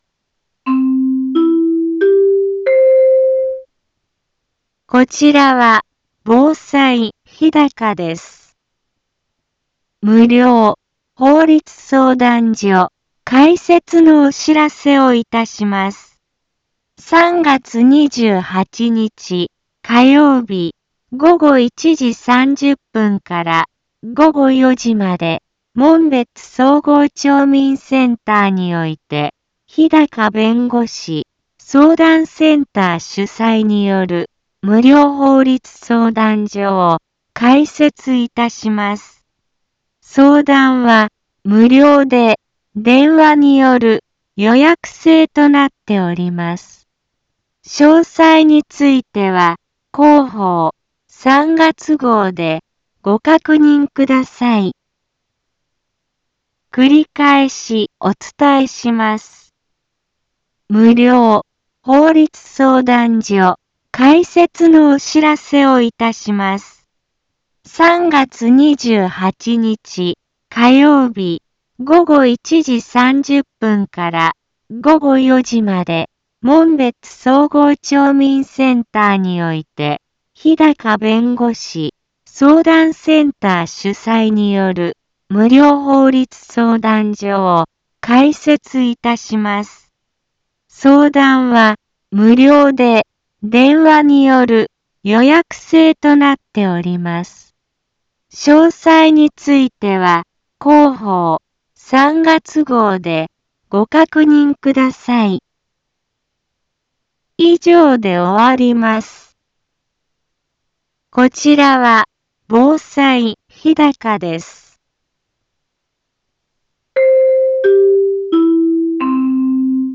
一般放送情報
Back Home 一般放送情報 音声放送 再生 一般放送情報 登録日時：2023-03-20 15:04:13 タイトル：無料法律相談会のお知らせ インフォメーション：こちらは防災日高です。 無料法律相談所開設のお知らせをいたします。